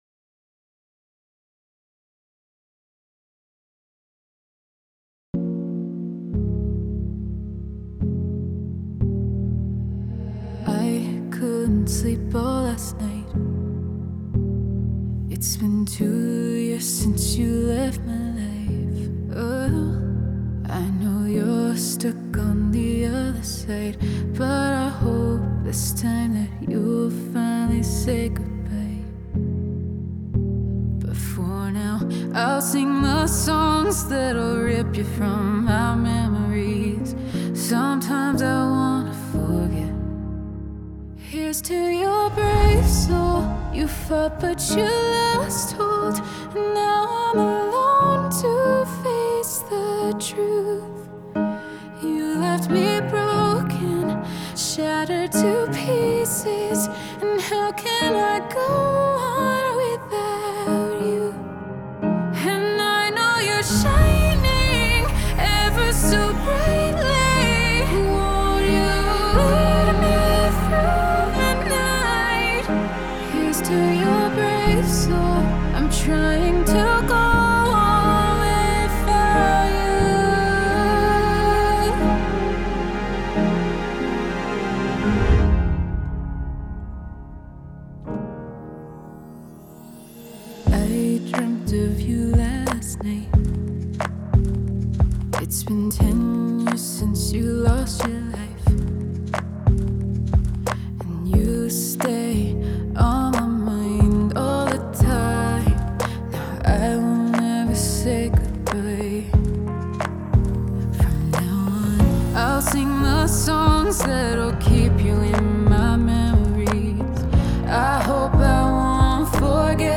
это трек в жанре EDM с элементами поп-музыки